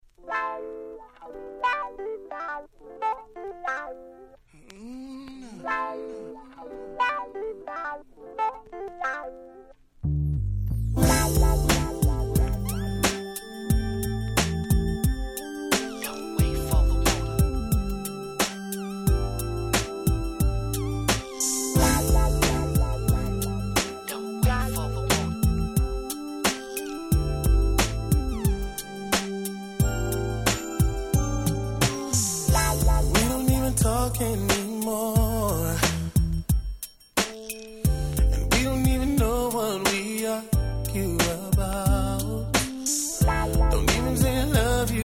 95' Smash Hit R&B !!
言わずもがなの彼らの完璧なコーラスワークが琴線にふれる素晴らしい1曲です。